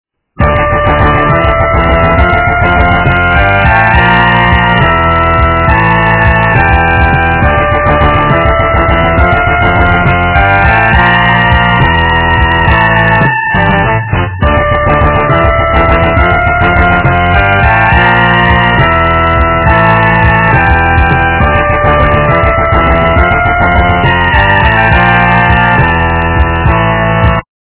- рок, металл